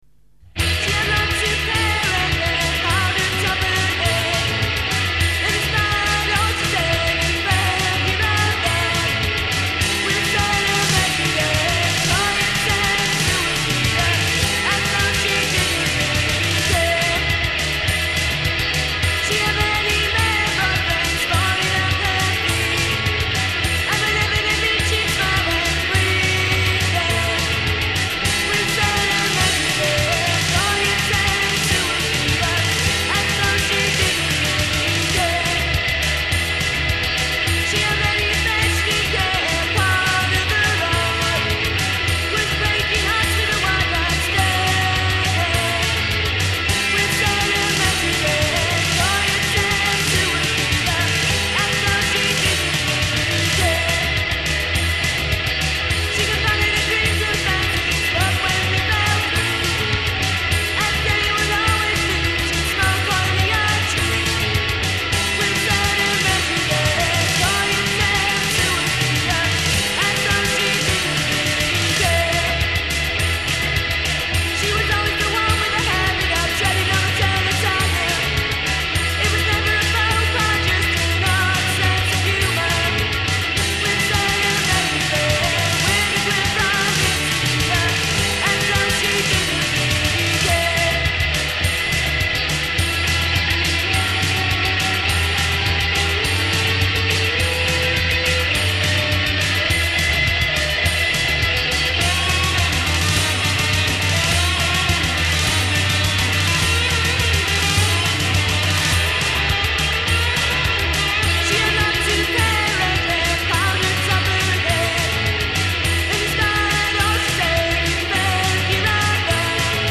From First demo, Cassette.